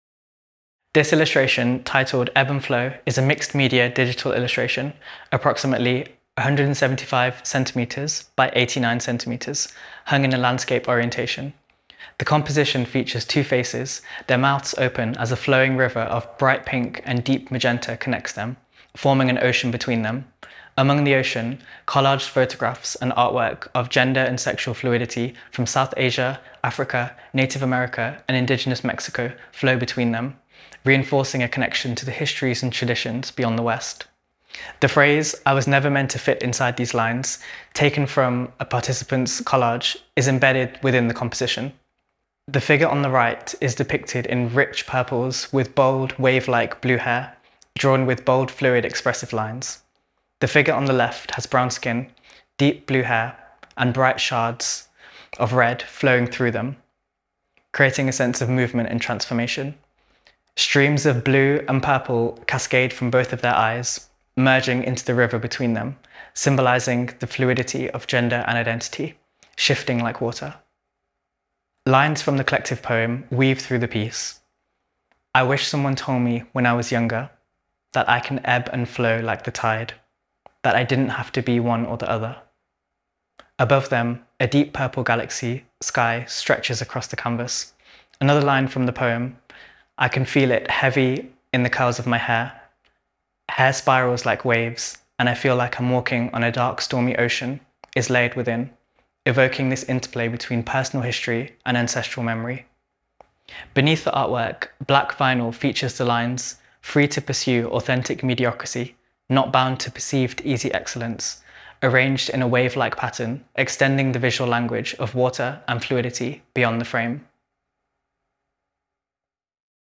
We Have Always Been Here – Audio Descriptions of Exhibition Artwork
Artwork descriptions – audio